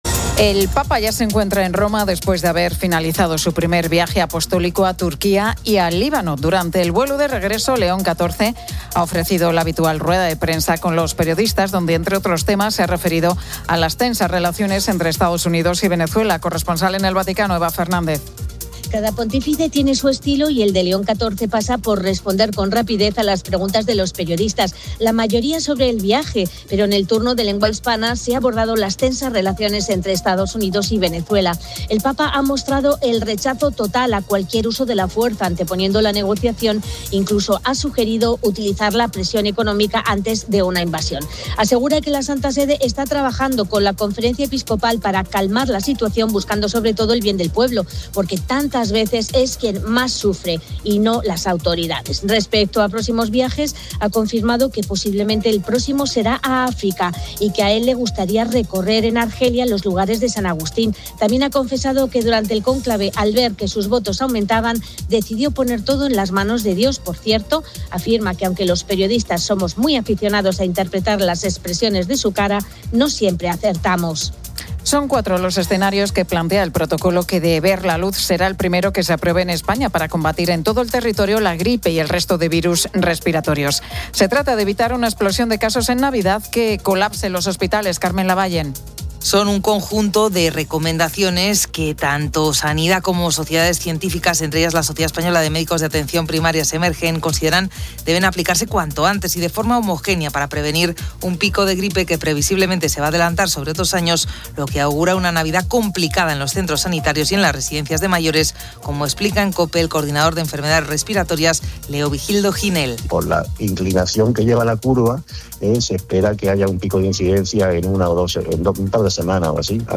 Y terminamos escuchando al coro gospel Living Water.